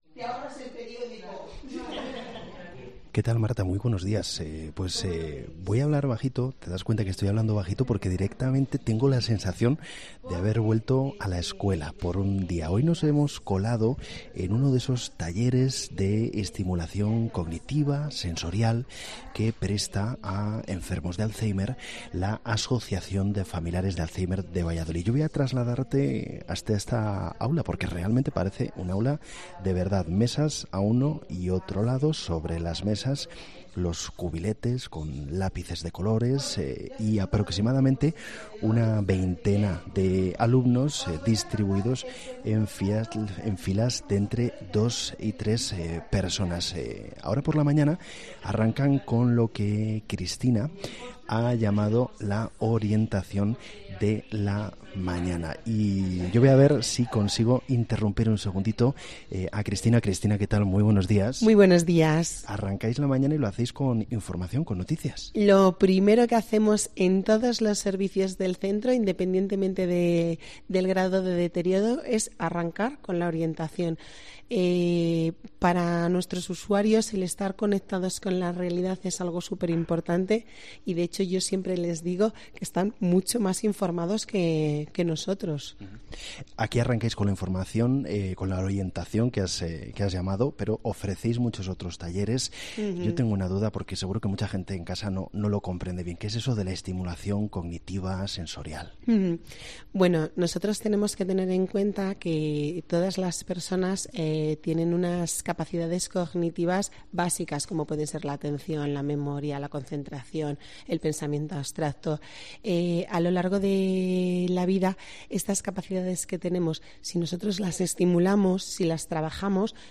COPE Valladolid asiste a un taller de estimulación para personas con Alzheimer: "Yo soy feliz aquí"